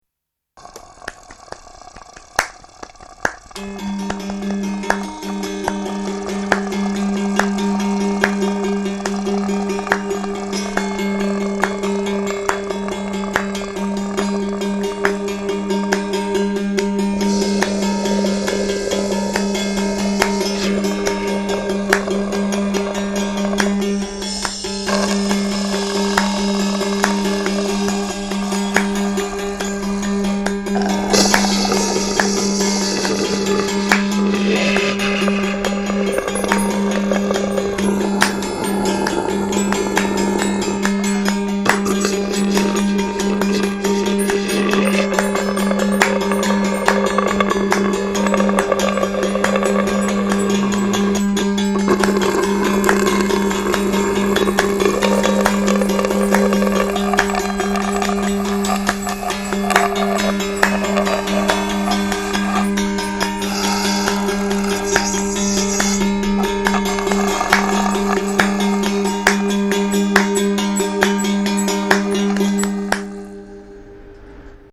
mélant musique concrète et instrument jouet.